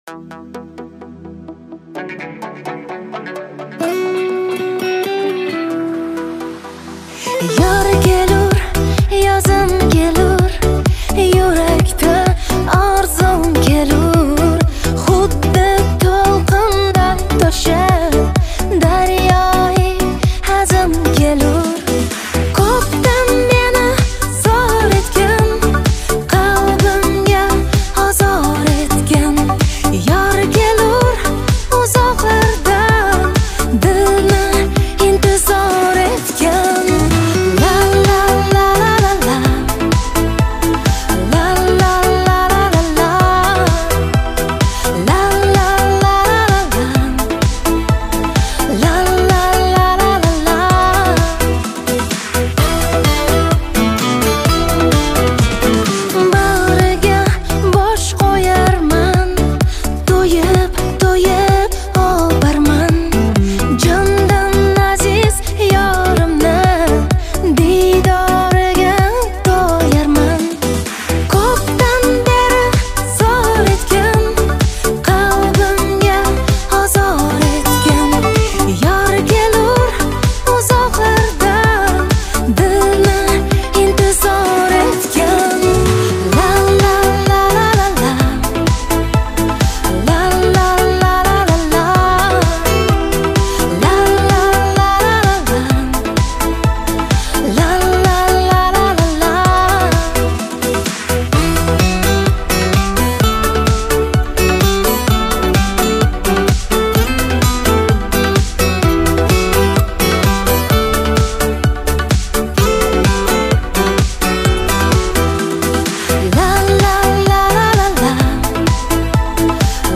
Трек размещён в разделе Узбекская музыка.